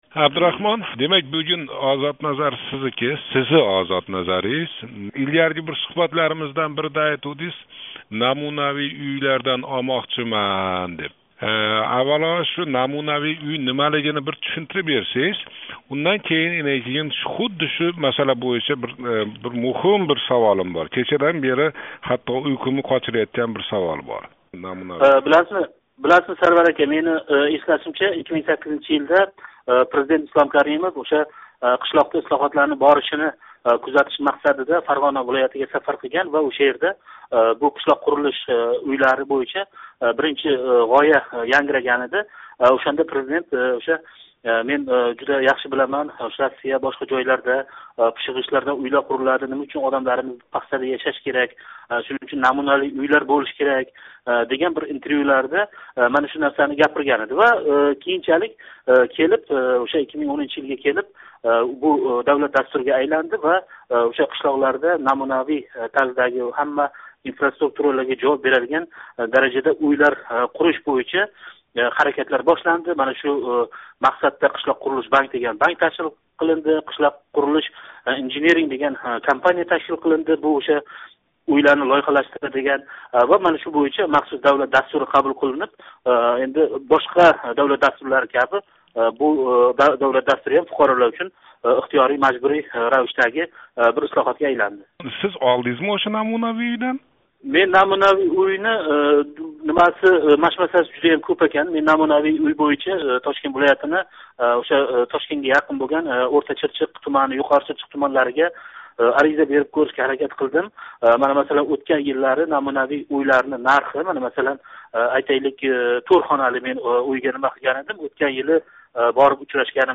ОзодНазар: